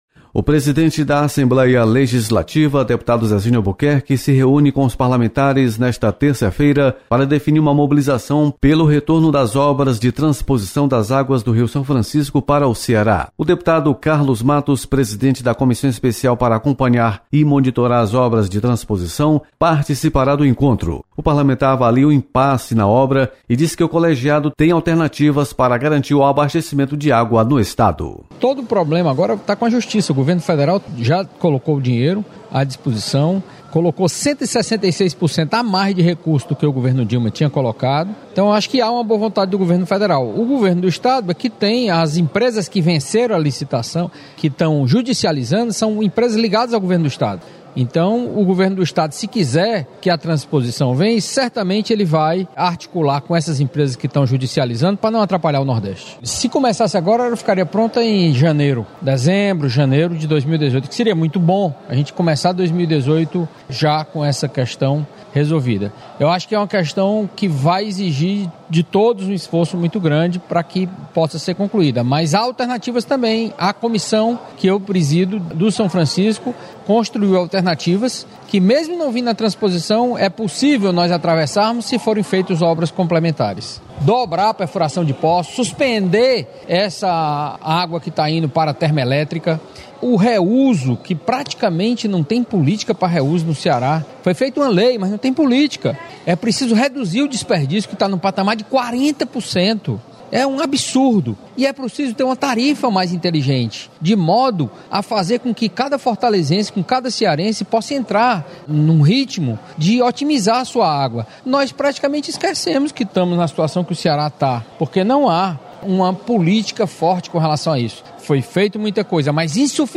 Deputado Carlos Matos participa de reunião pela retomada das obras de transposição do Rio São Francisco. Repórter